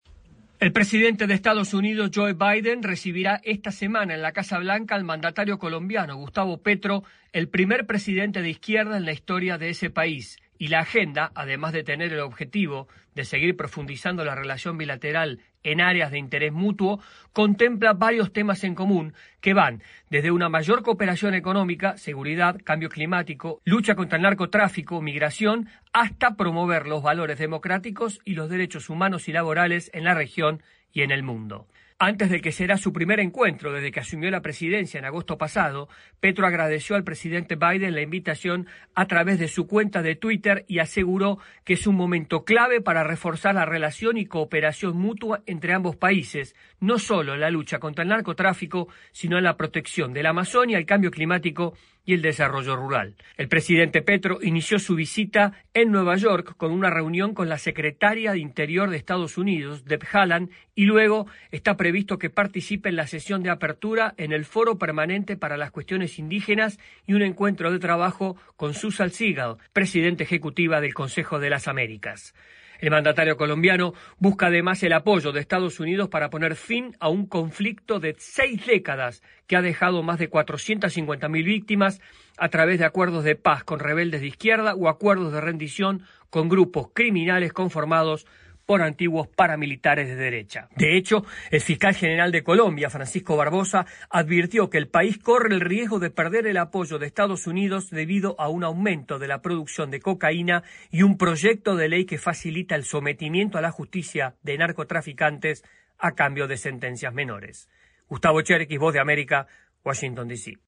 Desde Washington les informa